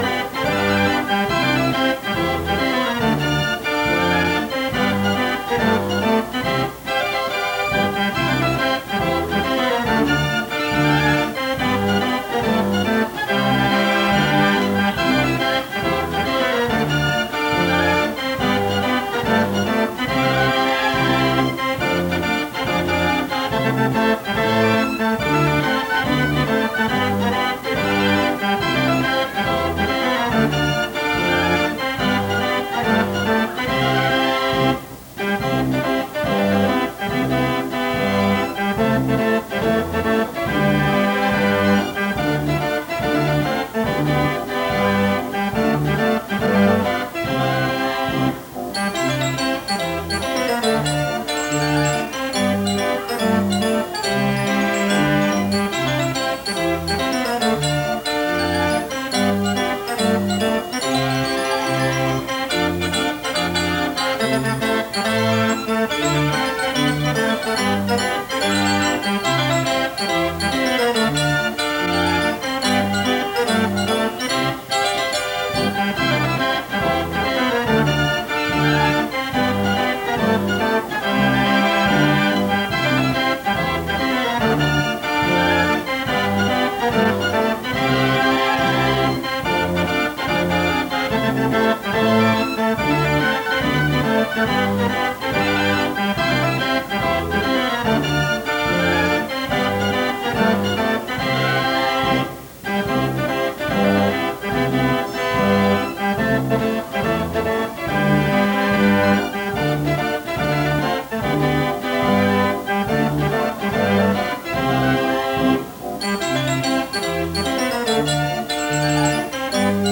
It had some lively music that would play at a carousel.
DD-Reel.mp3